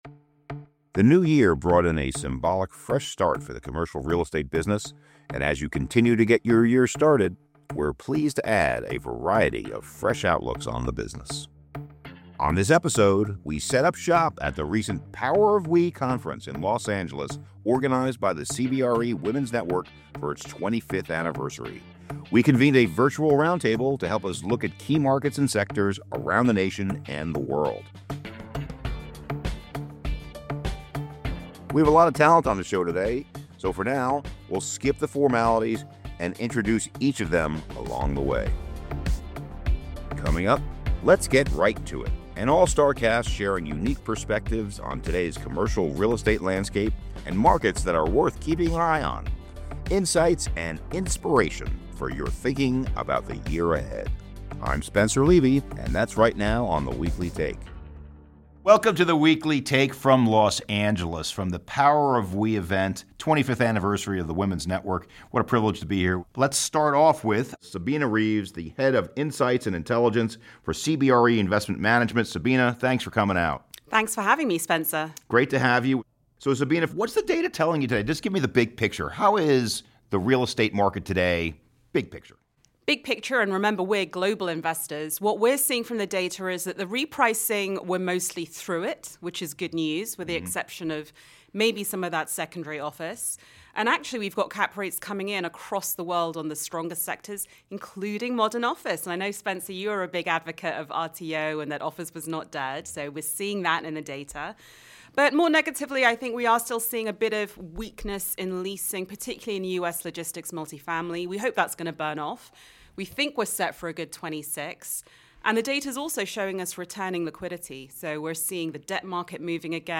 Recorded at the CBRE Women’s Network Power of WE conference, this episode offers a rapid-fire, insights-rich tour of major U.S. commercial real estate markets. Our subject-matter experts provide inside views of how different regions are navigating supply, demand and economic forces—from industrial and logistics to multifamily, office and retail—and insights on where investors and occupiers may find value in 2026.